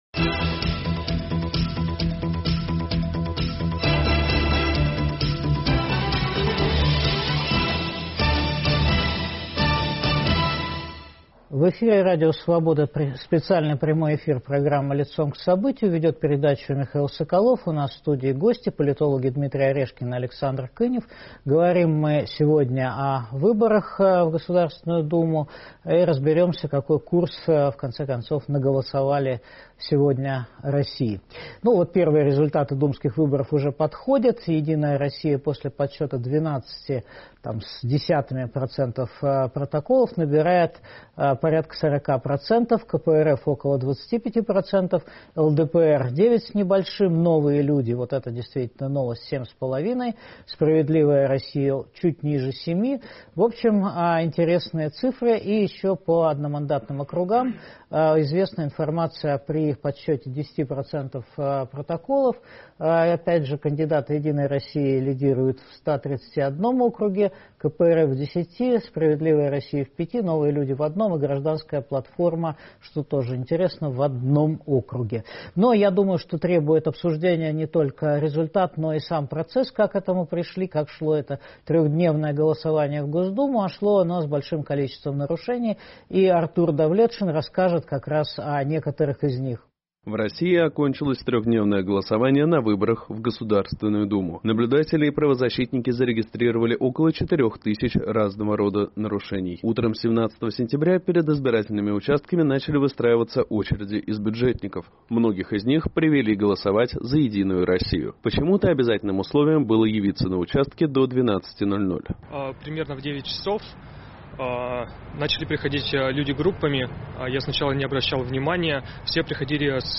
Специальный эфир программы «Лицом к событию».